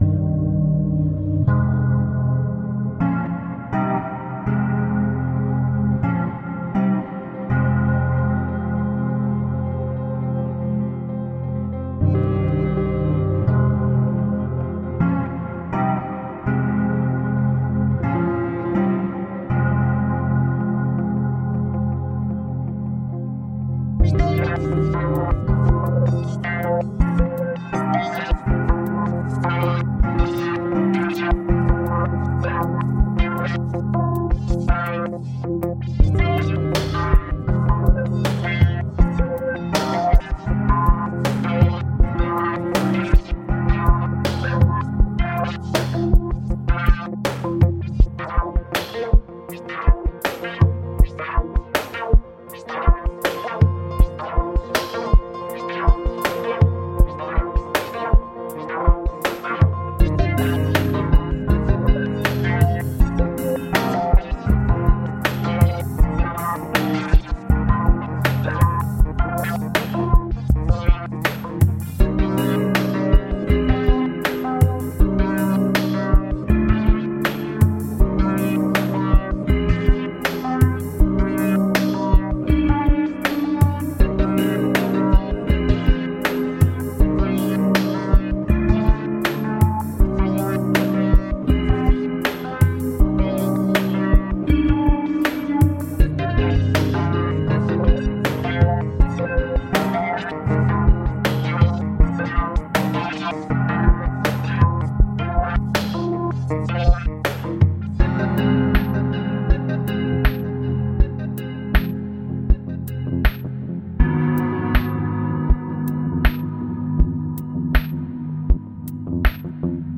Urban electronic music.
Tagged as: Electronica, Techno